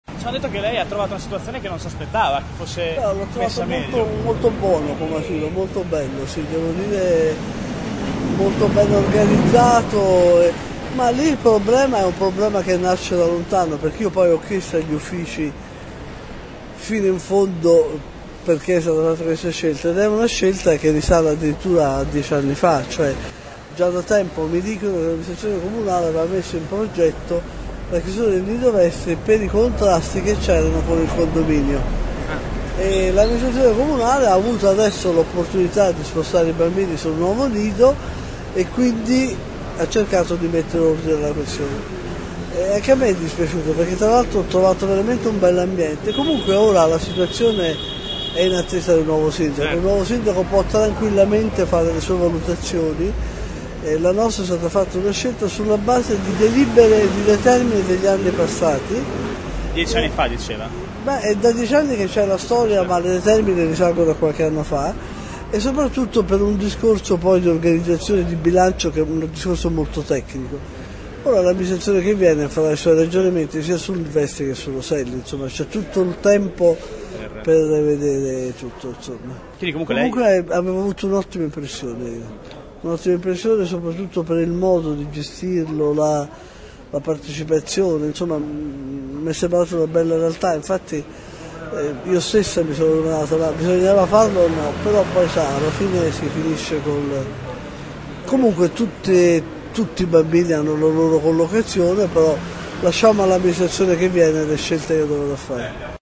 Sono le parole del commissario Annamaria Cancellieri sul Vestri, uno dei due nidi a rischio chiusura che ora è stata congelata. Il commissario ha spiegato ai nostri microfoni di aver chiesto “fino in fondo agli uffici cosa era accaduto” e che la decisione risale nel tempo per “problemi con il condominio.